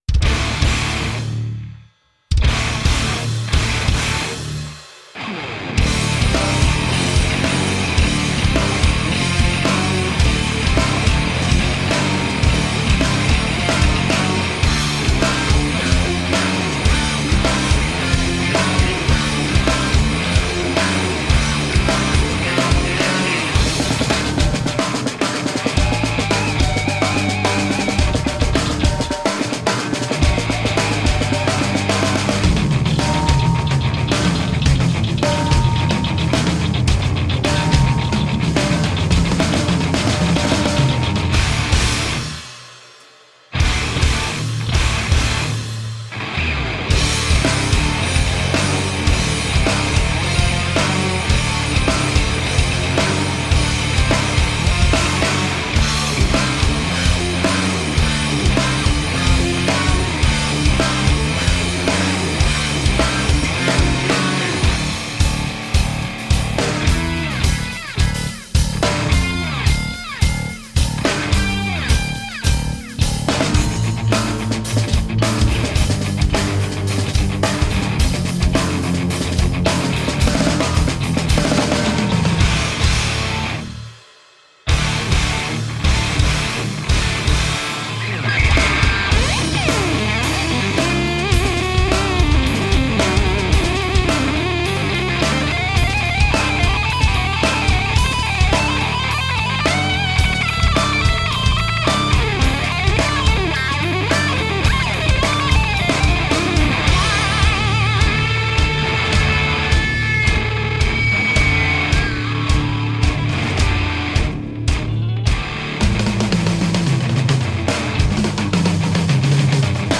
A solid hybrid sound of instrumental rock.
Tagged as: Hard Rock, Rock, Metal, Instrumental